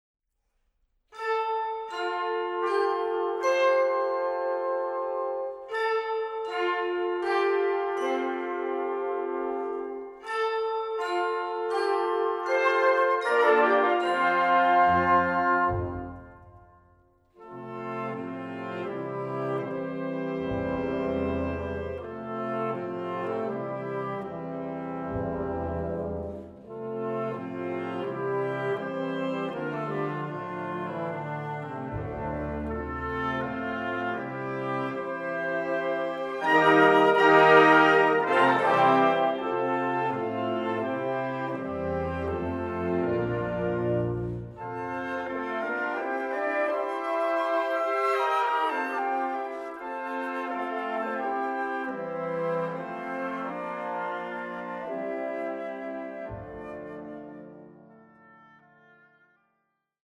Music for Symphonic Wind Orchestra